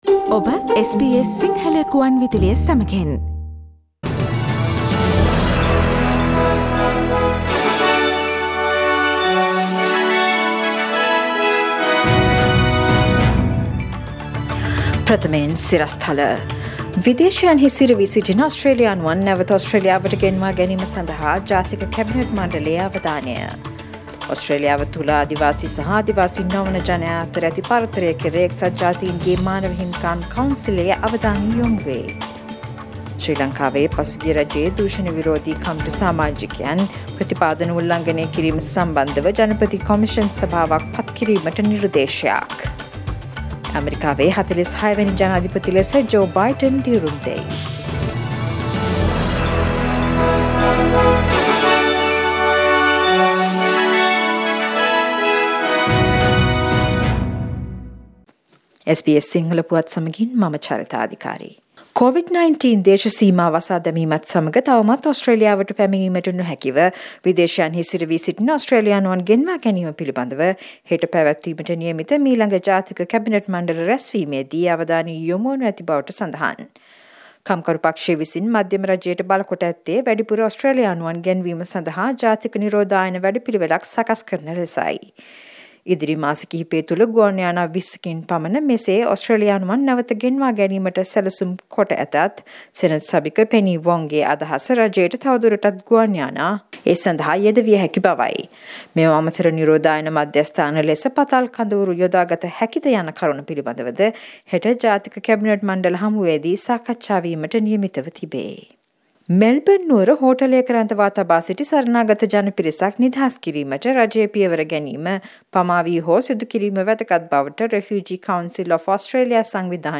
Today’s news bulletin of SBS Sinhala radio – Thursday 21 January 2021